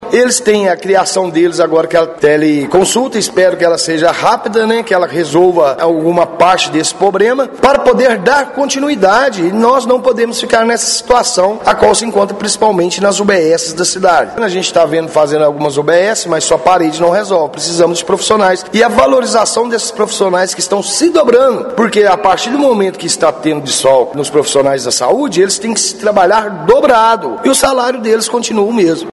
O vereador também confirmou a expectativa de melhorias com a implementação da telemedicina e outras ações voltadas à valorização dos profissionais de saúde. Mas deixou claro que, por enquanto, os serviços estão aquém do esperado: